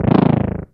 bad_explosion1.wav